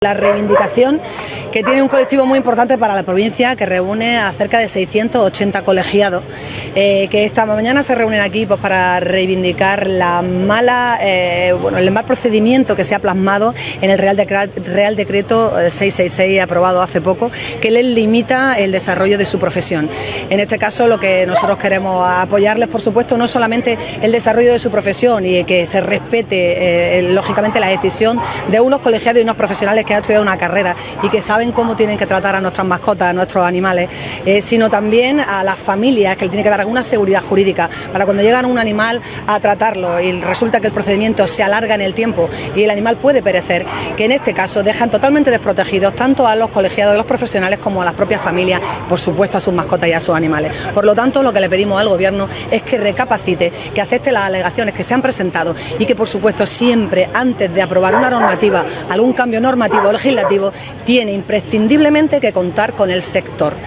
ALCALDESA-MARCHA-REIVINDICATIVA-VETERINARIOS.wav